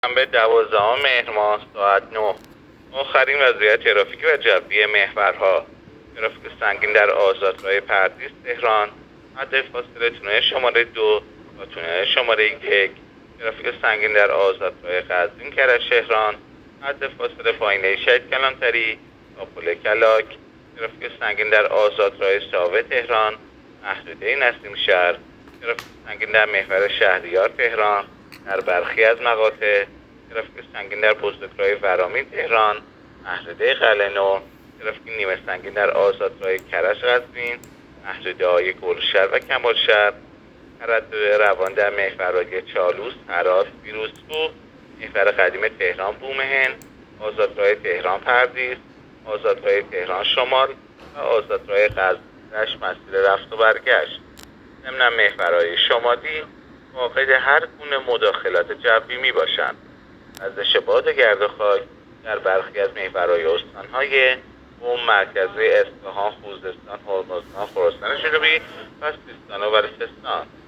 گزارش رادیو اینترنتی از آخرین وضعیت ترافیکی جاده‌ها ساعت ۹ دوازدهم مهر؛